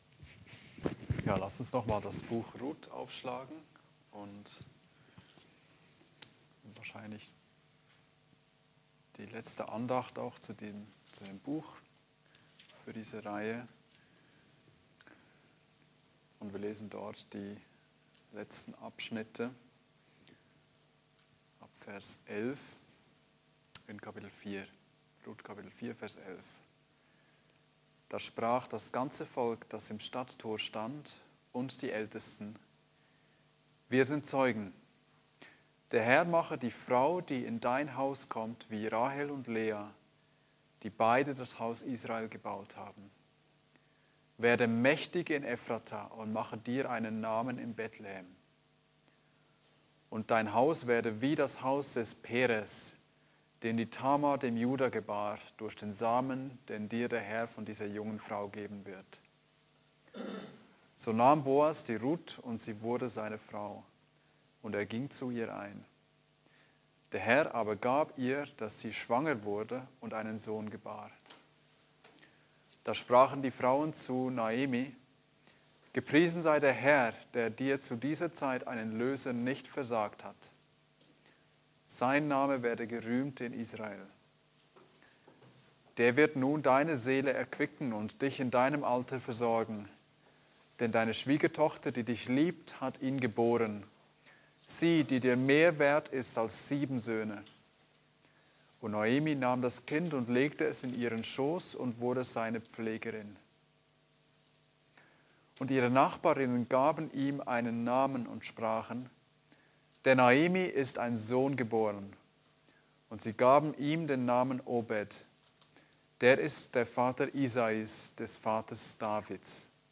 Gott der mehreren Generationen (Andacht Gebetsstunde)